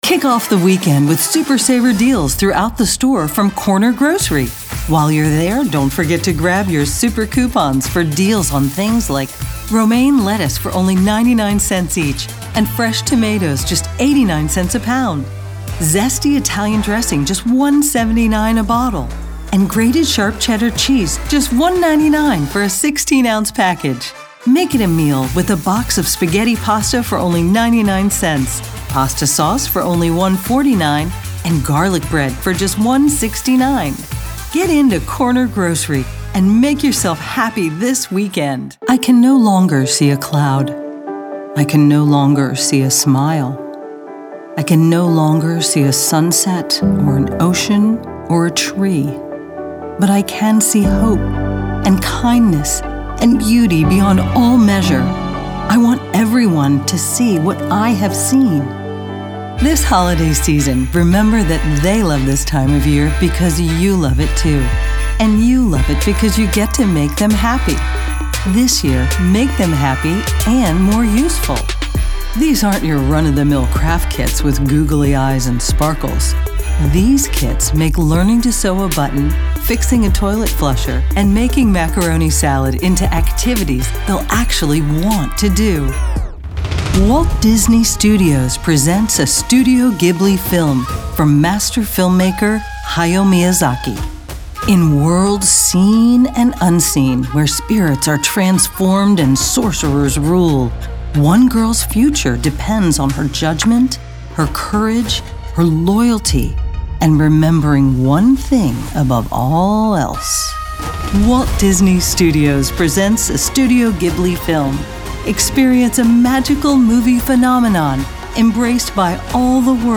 attitude, caring, confessional, confident, cool, friendly, girl-next-door, high-energy, inspirational, motivational, movie-trailer, perky, retail, thoughtful, upbeat, warm